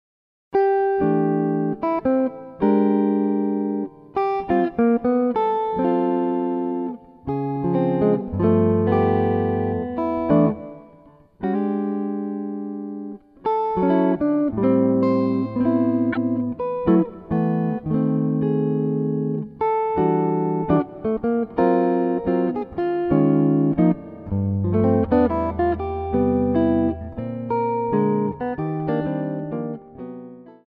solo electric guitar